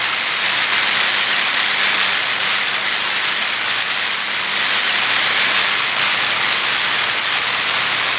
electric_sparks_lightning_loop6.wav